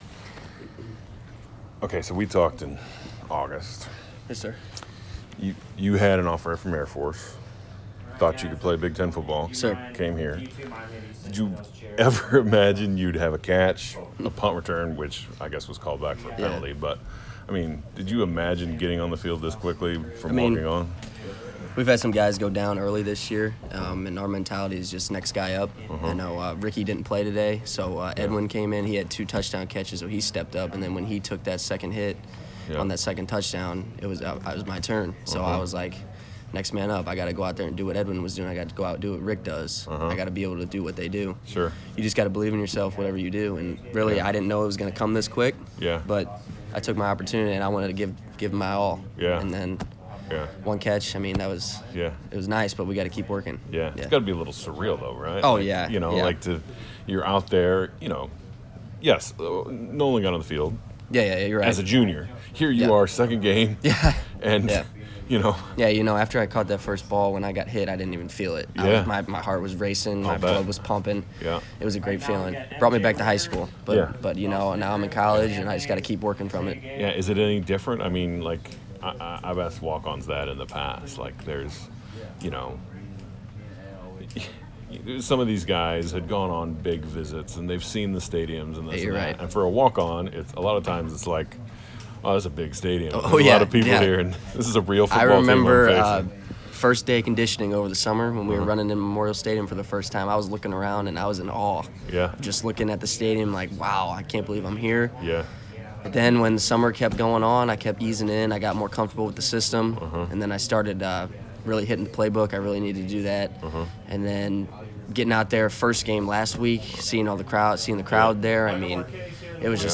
Walkon Interviews